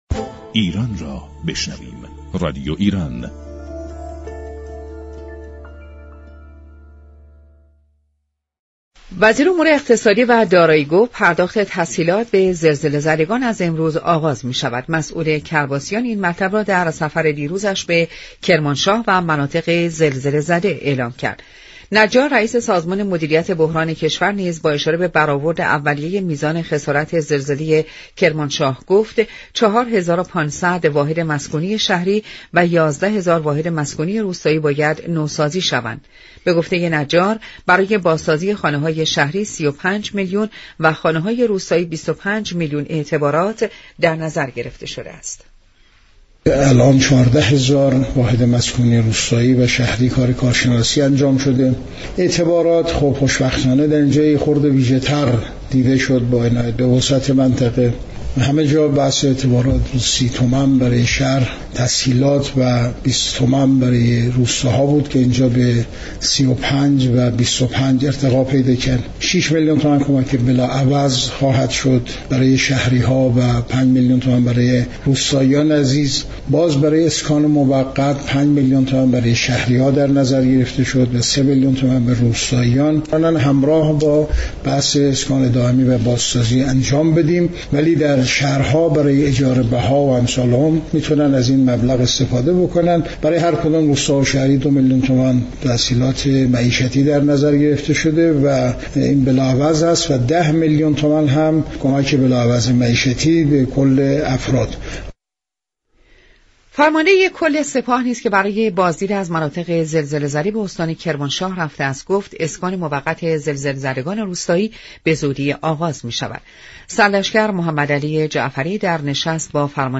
اخباری كوتاه از زلزله كرمانشاه